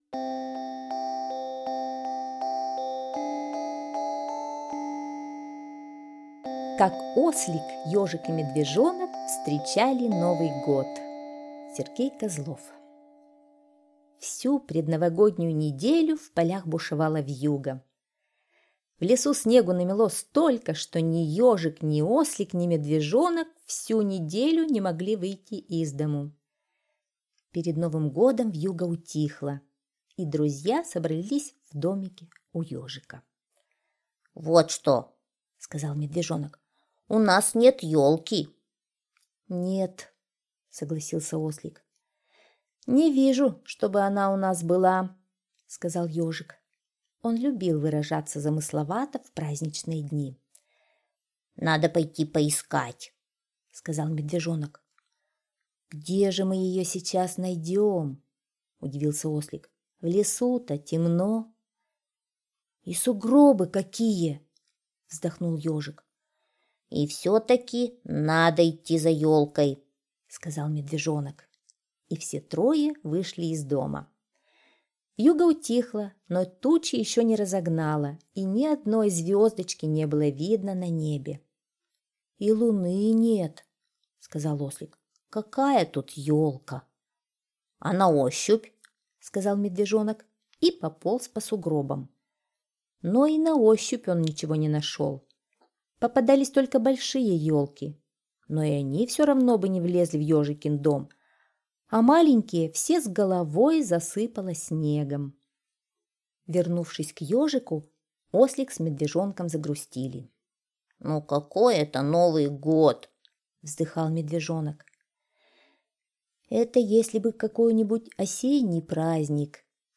Слушайте Как Ослик, Ёжик и Медвежонок встречали Новый год - аудиосказка Козлова С.Г. Сказка про Ослик, Ежик и Медвежонок на Новый год елку искали.